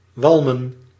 Ääntäminen
IPA: [fʁe.miʁ]